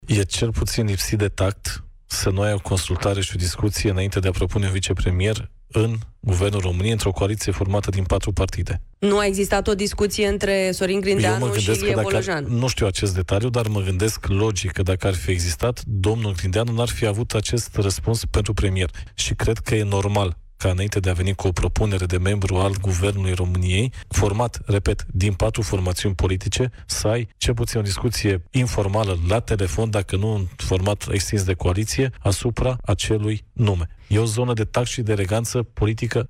Ar fi vorba – spune el – de lipsă de tact și de diplomație politică. Declarațiile au fost făcute în cadrul emisiunii „Piața Victoriei”